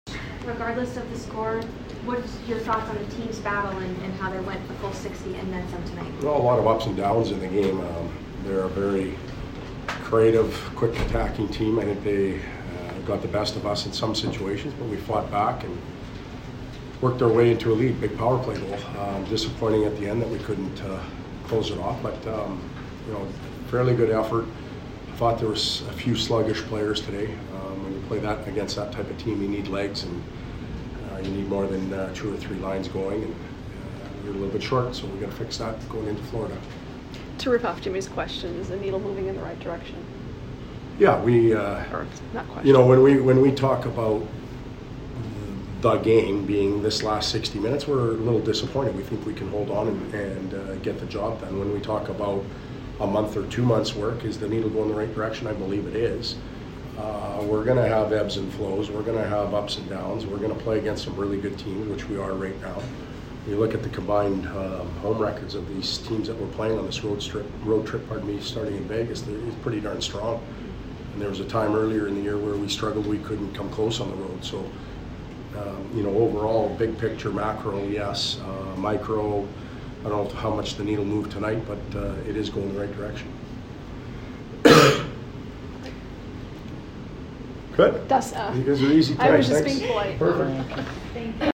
Todd McLellan post-game 1/14